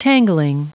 Prononciation du mot tangling en anglais (fichier audio)
Prononciation du mot : tangling